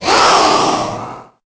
Cri de Roublenard dans Pokémon Épée et Bouclier.